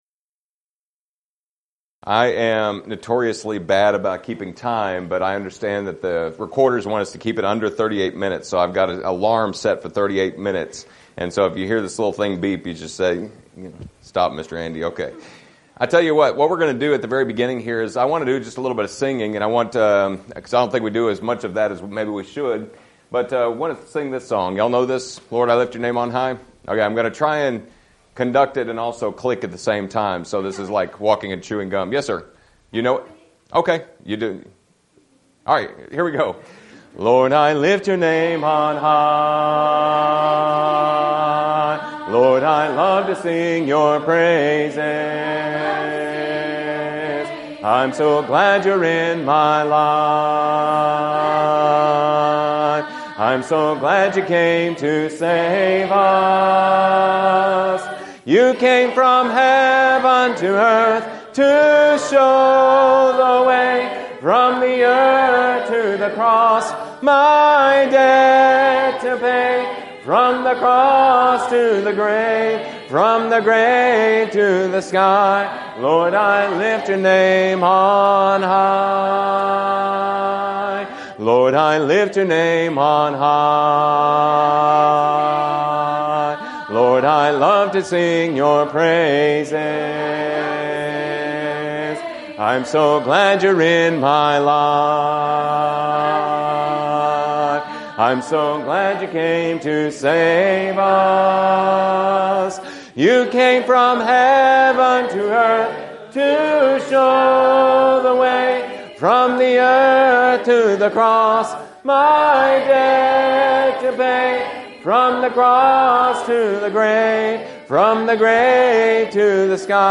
Preacher's Workshop
Youth Sessions